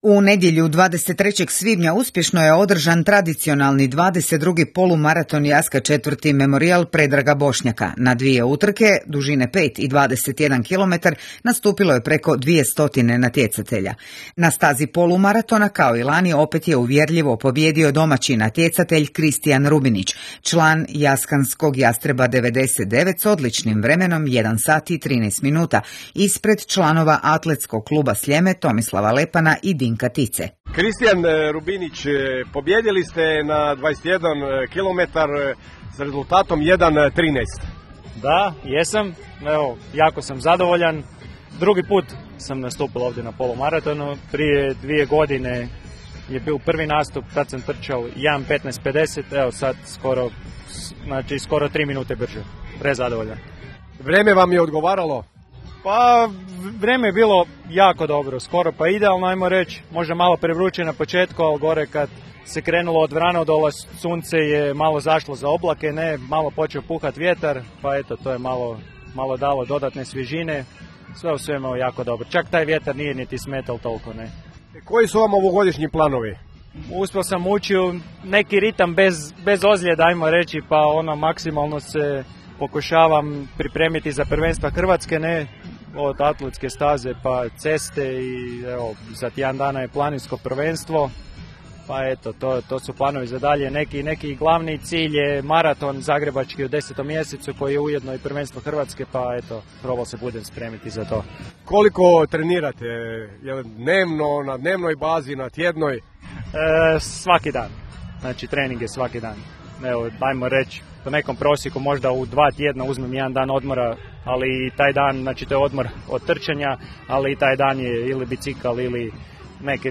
U nastavku donosimo opširan audio prilog s 22. Jaskanskog polumaratona i 4. Memorijala Predraga Bošnjaka.